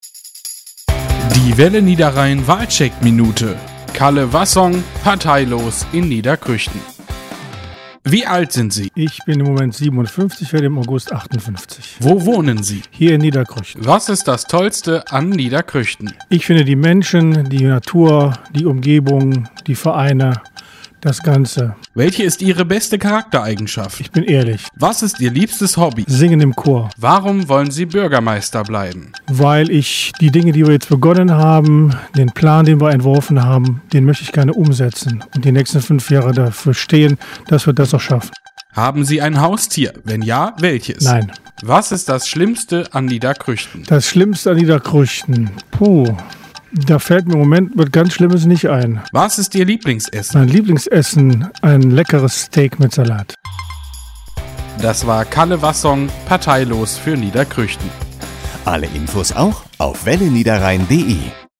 Wir treffen den amtierenden Bürgermeister zum Gespräch in seiner Gemeinde. Wassong spricht hier über seine "Guten Aussichten für alle" und weitere Ziele für eine mögliche nächste Amtszeit im Niederkrüchtener Rathaus: